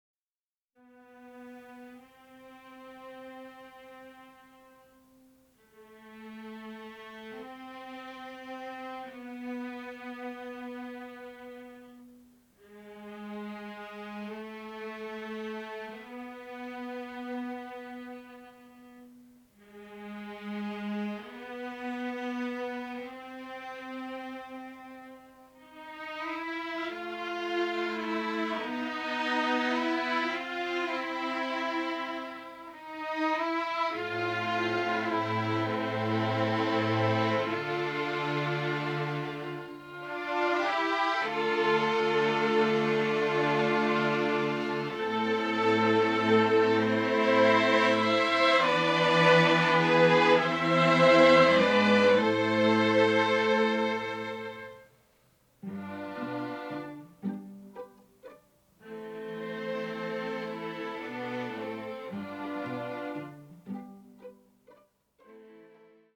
in stereo and mint condition